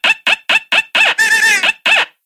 короткие
очень громкие
Забавный звук на смс